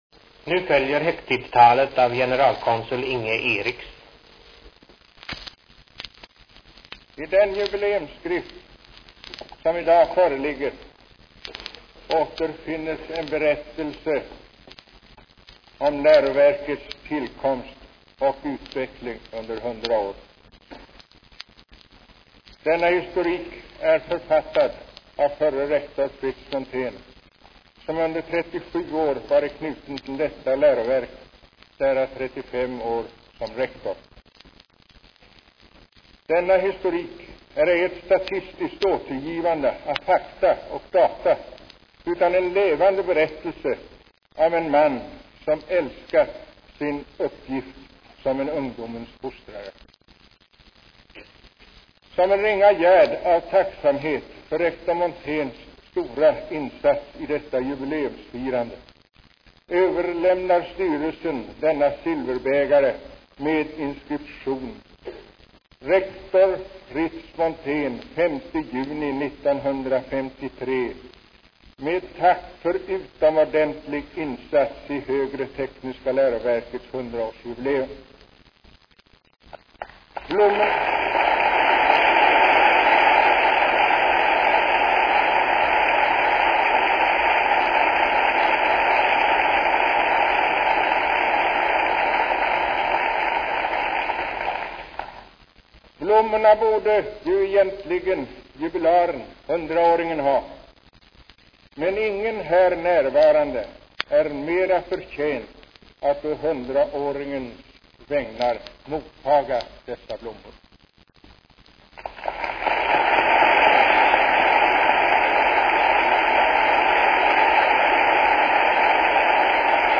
Nedan följer inspelningar från Sveriges Radios bevakning. Inspelningarna kommer från lackskivor som nyligen hittades i samband med ombyggnad av skolan.